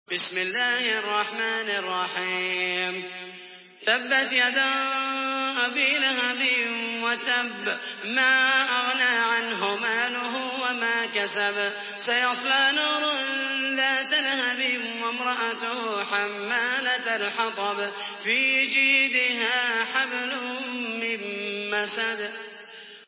Surah Al-Masad MP3 by Muhammed al Mohaisany in Hafs An Asim narration.
Murattal Hafs An Asim